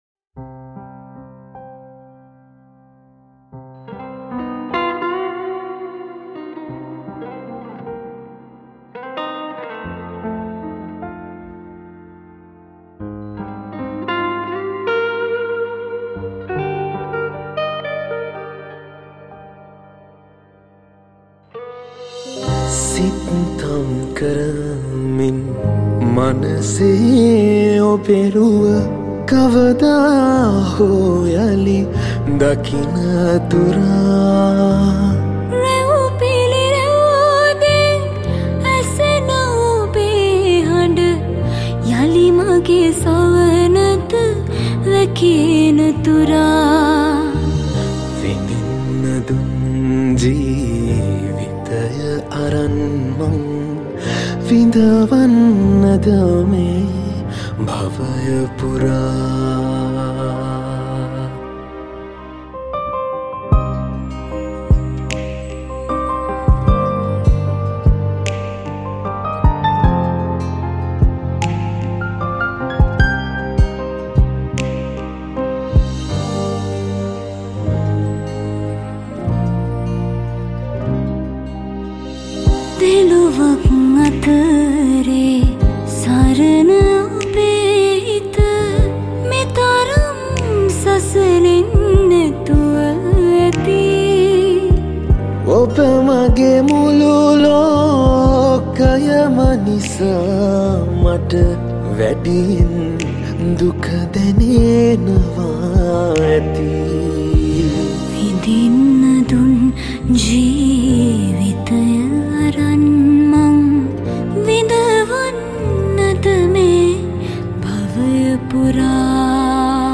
Cover Vocals